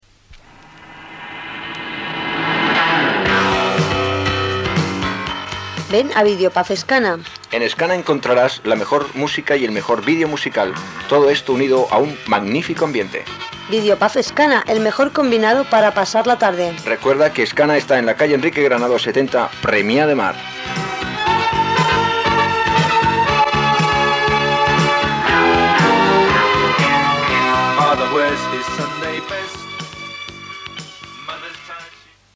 Comercial
FM